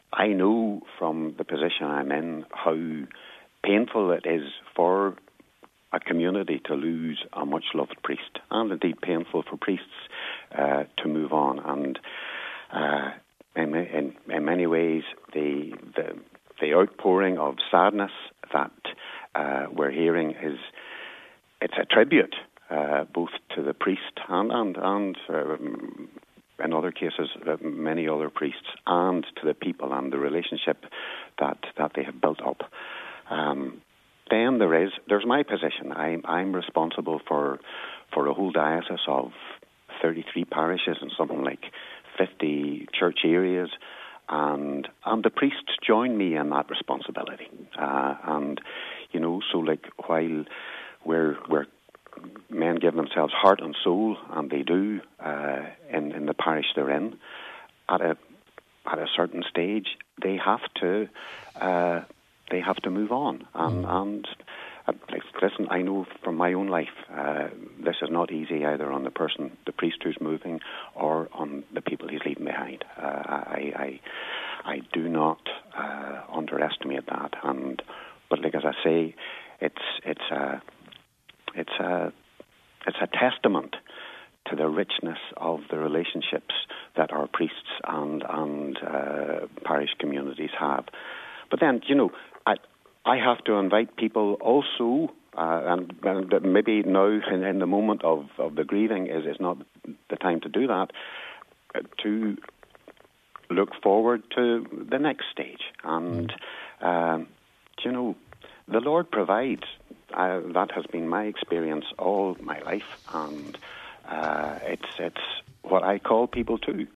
Bishop McGuckian told today’s Nine til Noon Show